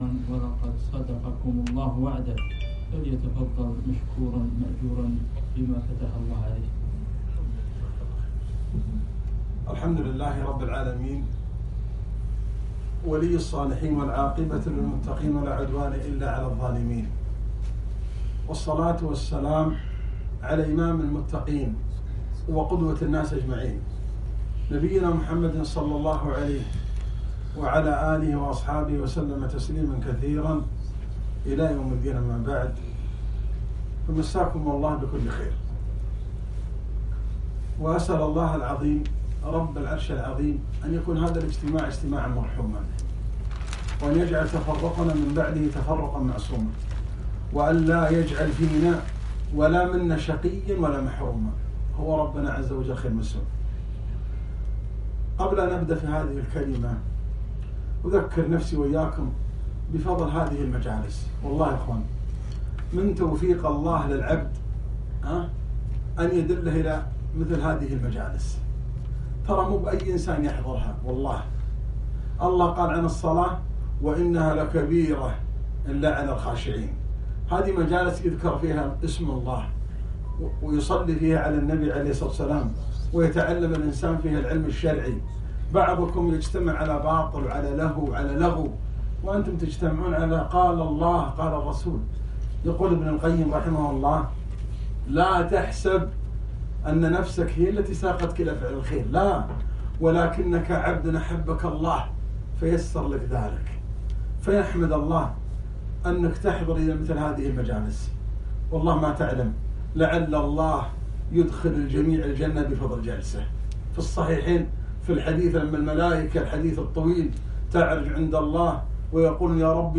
محاضرة - (ولقد صدقكم الله وعده)